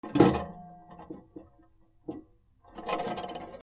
はかり
体重を量る ばねばかり 原音あり R26OM